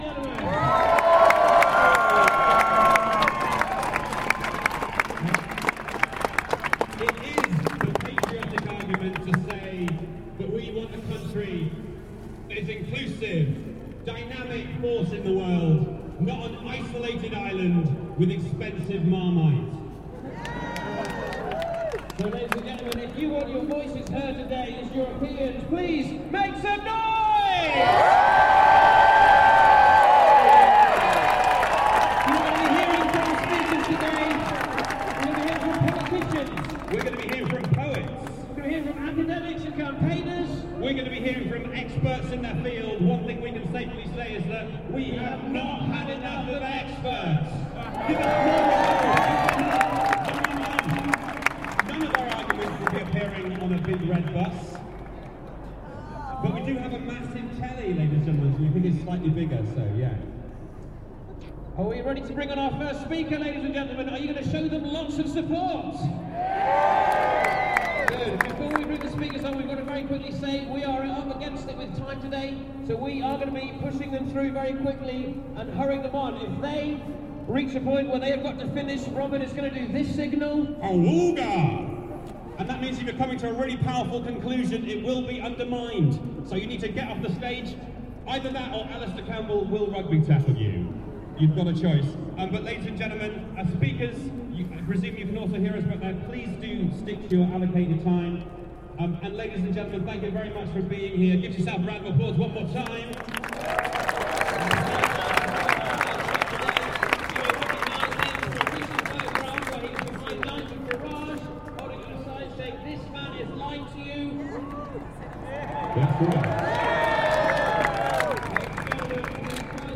Two comedians introduce an afternoon of speeches in Parliament Square, London as part of the pro-EU Unite for Europe march.